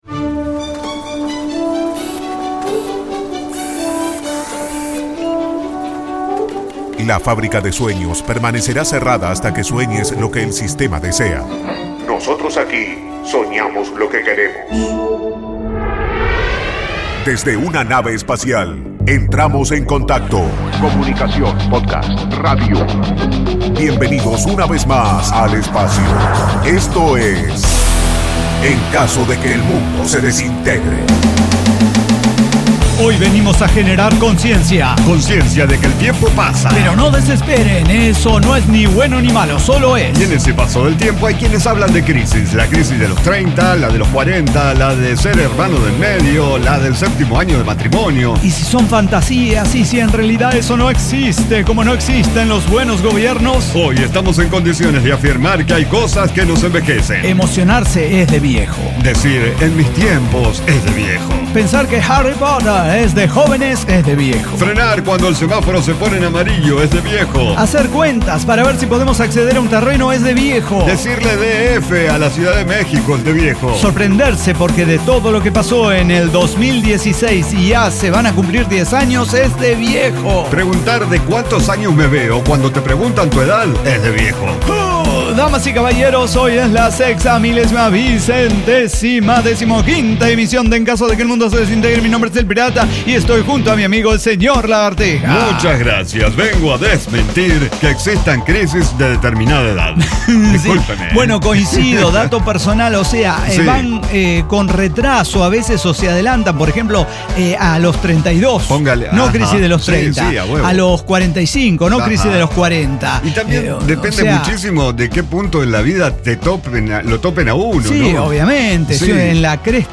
ECDQEMSD El Cyber Talk Show - Noticias, la comedia y el drama del devenir diario.
Diseño, guionado, música, edición y voces son de nuestra completa intervención humana.